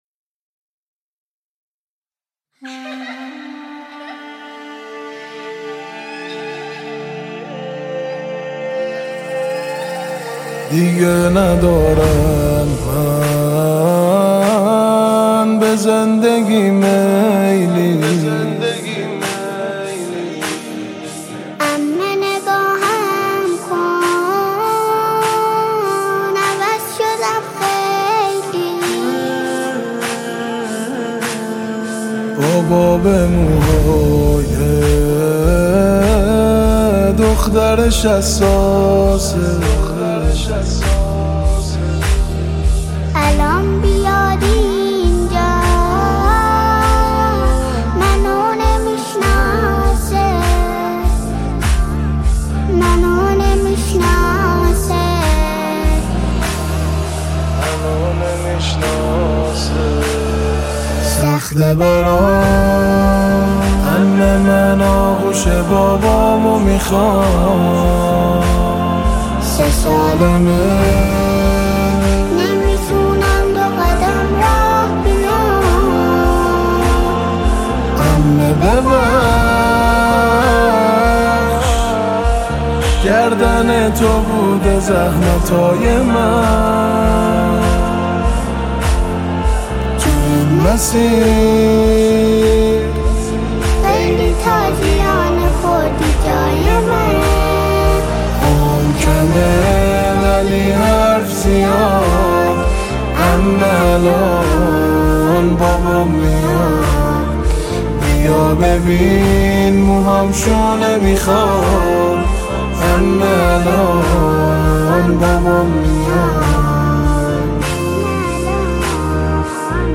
مداحی شهادت حضرت رقیه